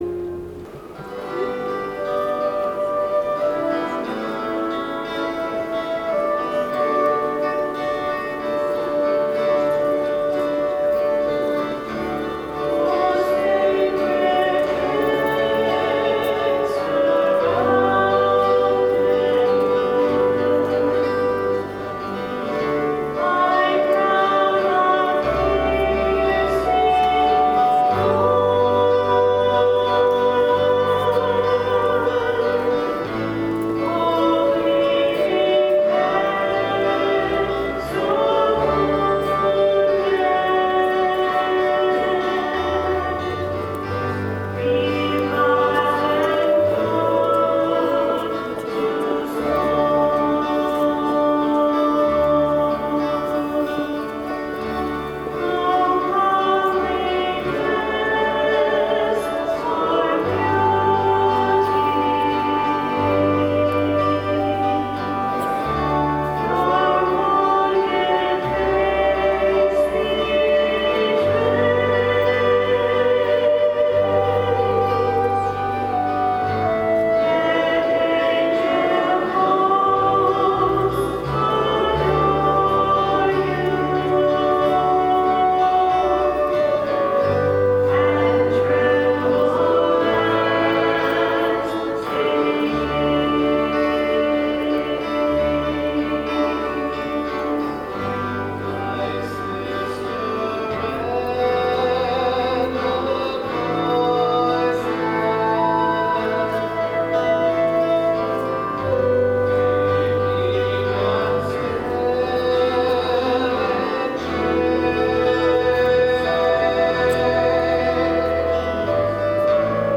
Music from the 10:30 Mass on the 1st Sunday in Lent, March 24, 2013: